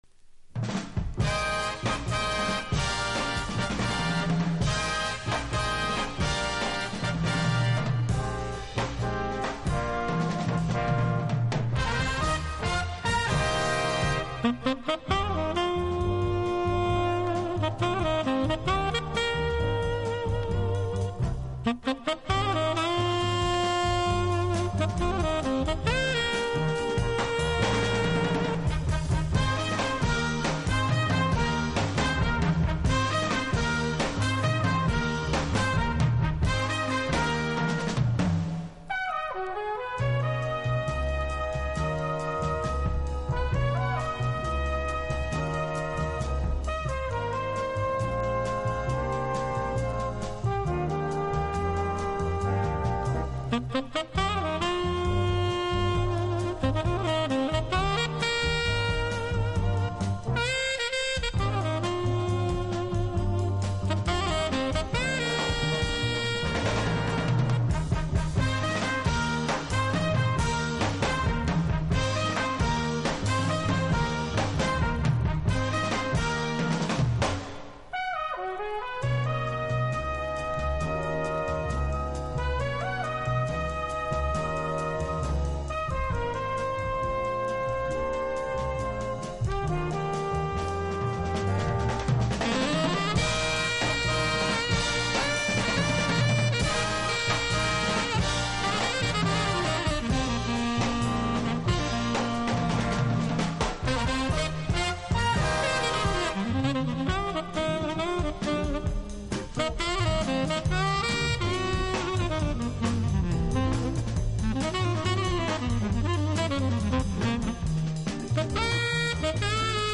（プレスによりチリ、プチ音ある曲あり）
ステレオ
Genre US JAZZ